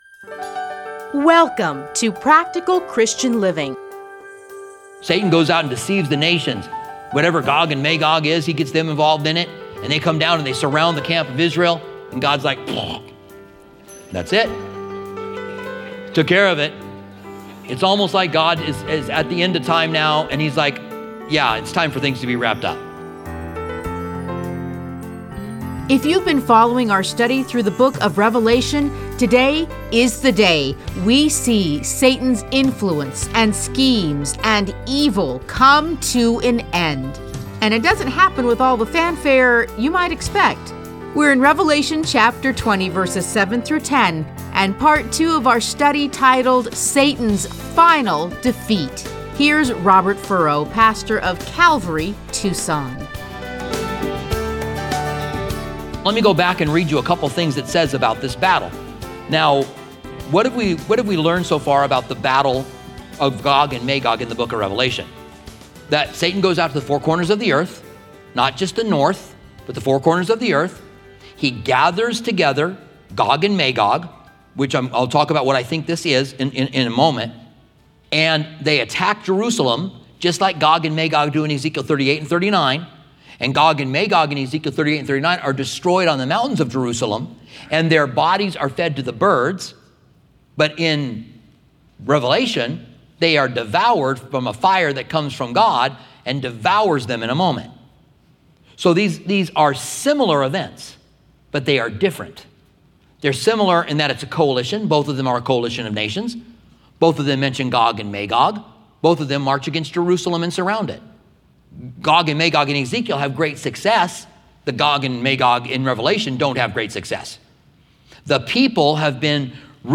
Listen to a teaching from Revelation 20:7-10.